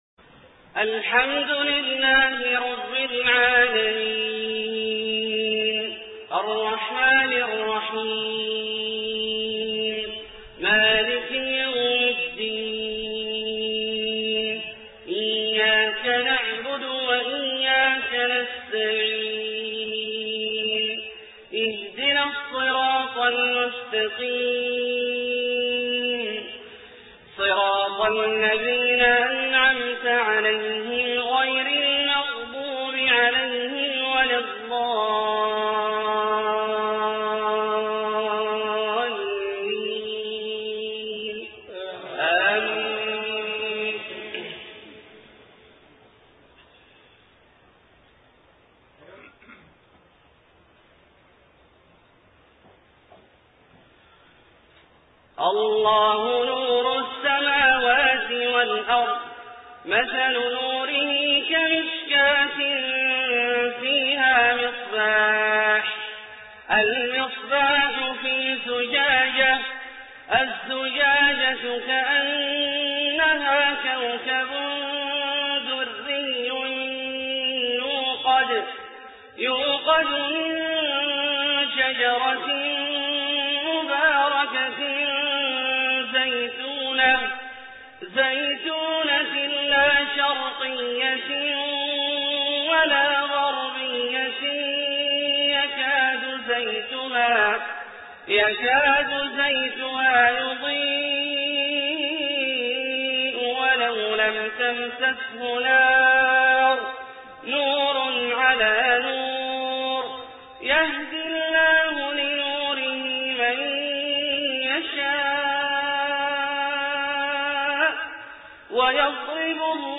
صلاة المغرب 9-9-1428 من سورة النور 35-39 > ١٤٢٨ هـ > الفروض - تلاوات عبدالله الجهني